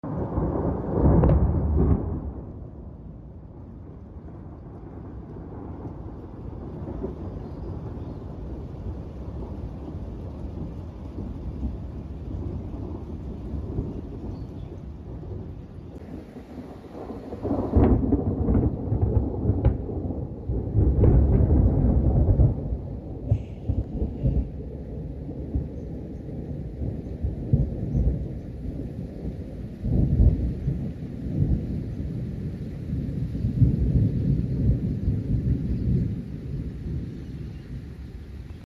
Nice Loud Claps Of Thunder Sound Effects Free Download